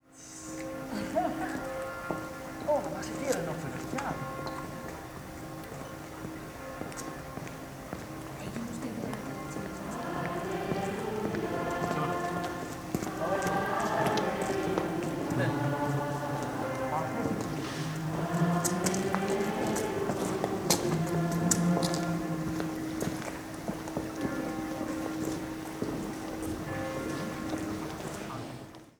Piazza in Cembra, Italy, with three components to the auditory scene (foreground events, bell in distance, church interior with choir in middle)
Vancouver Stock Exchange, trading floor, 1970s, with competing voices